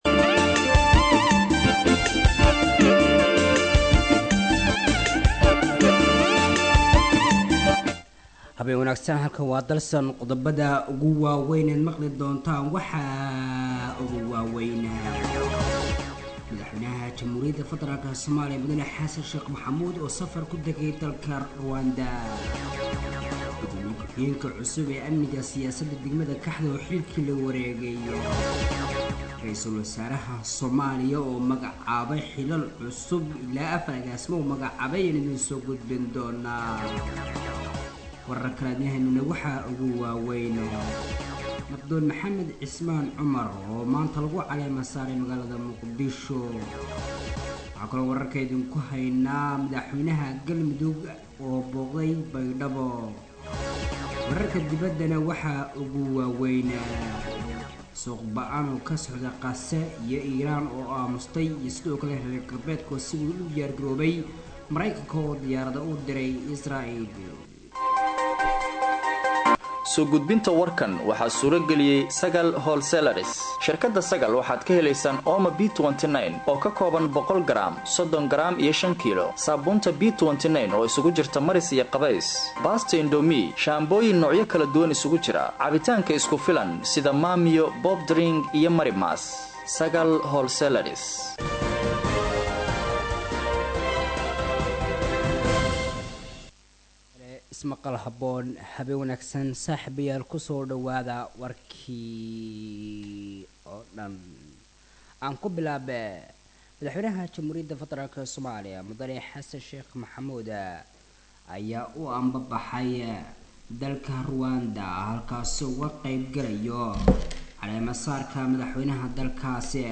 HalkanÂ Ka Dhageyso WarkaÂ Habeenimo Ee Radio Dalsan:-